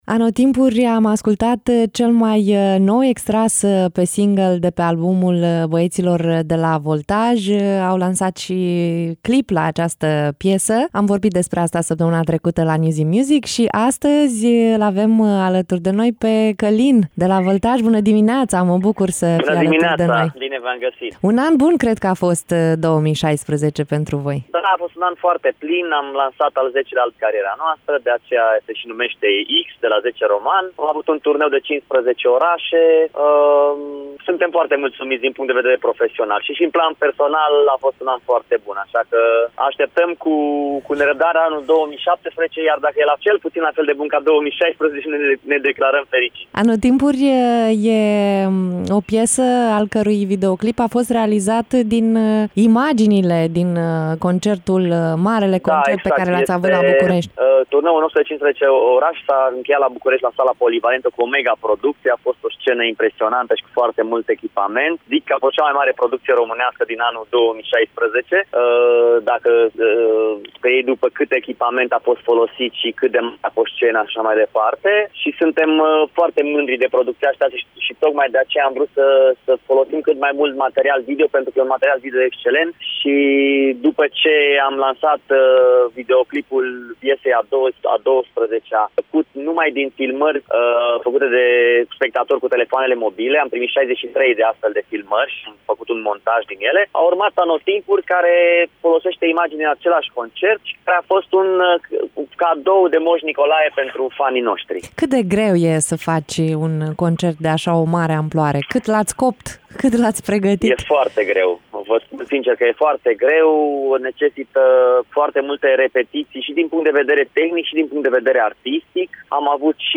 17-dec-interviu-calin-voltaj.mp3